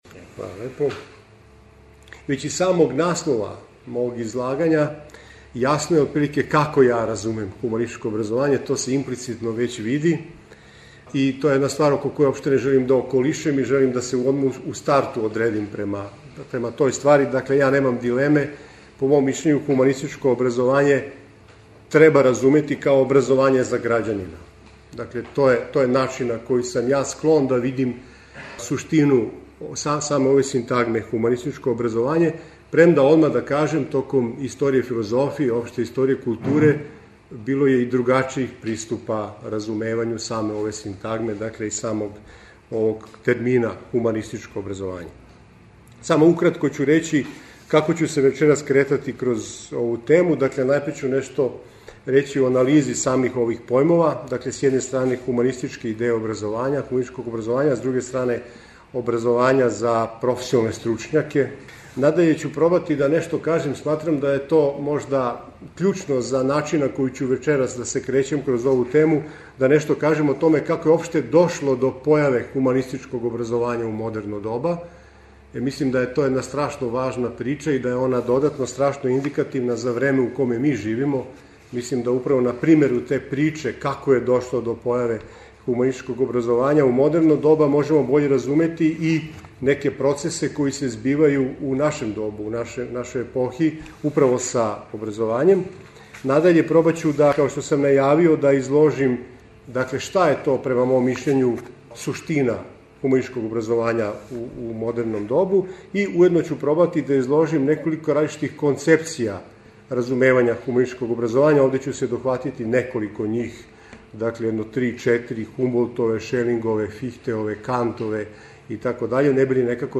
Предавања